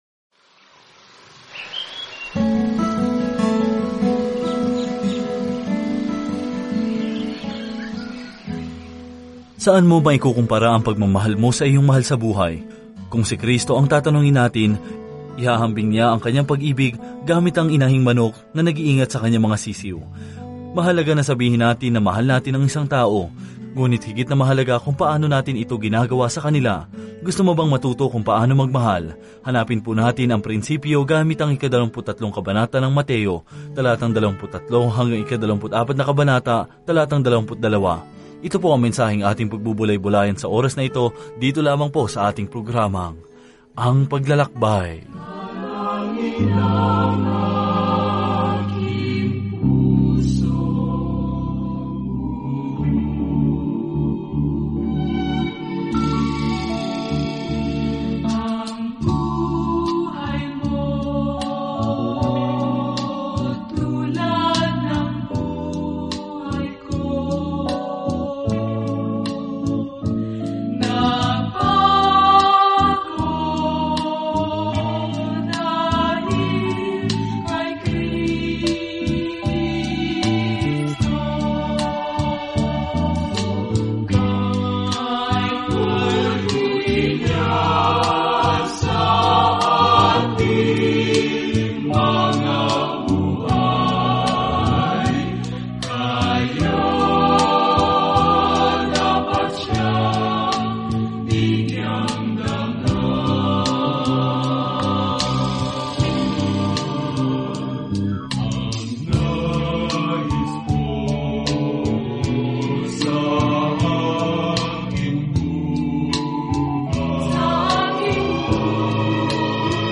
Banal na Kasulatan Mateo 23:23-39 Mateo 24:1-3 Araw 31 Umpisahan ang Gabay na Ito Araw 33 Tungkol sa Gabay na ito Pinatunayan ni Mateo sa mga Judiong mambabasa ang mabuting balita na si Jesus ang kanilang Mesiyas sa pamamagitan ng pagpapakita kung paano natupad ng Kanyang buhay at ministeryo ang hula sa Lumang Tipan. Araw-araw na paglalakbay sa Mateo habang nakikinig ka sa audio study at nagbabasa ng mga piling talata mula sa salita ng Diyos.